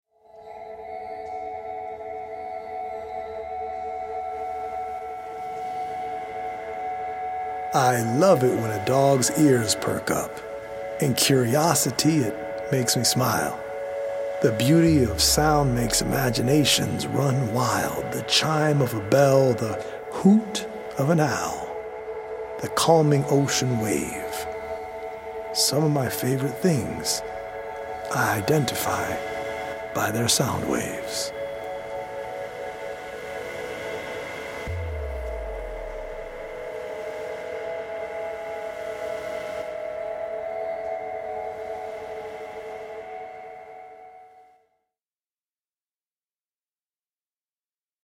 healing Solfeggio frequency music
EDM producer